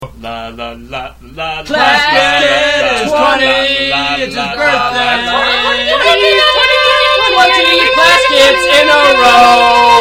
a capella